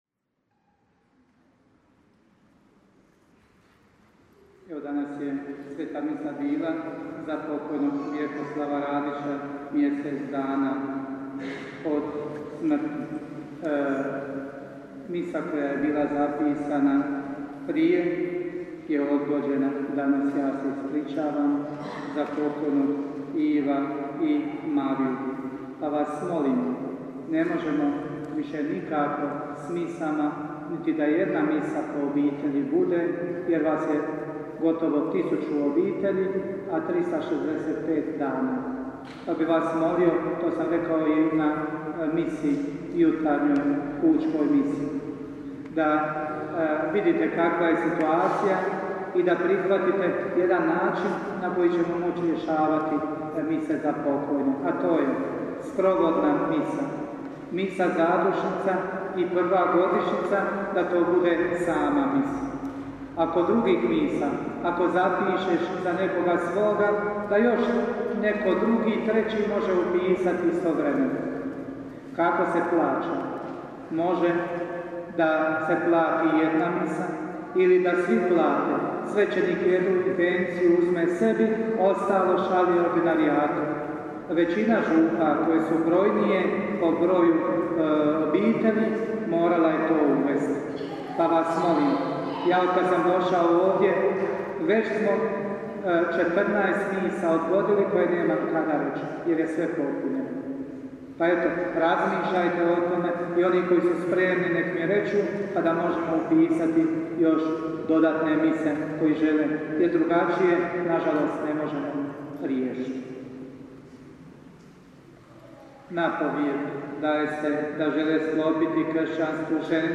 župne obavjesti (oglasi i napovjedi):